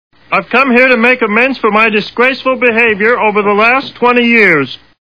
The Simpsons [Barney] Cartoon TV Show Sound Bites